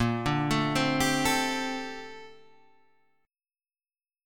BbM13 chord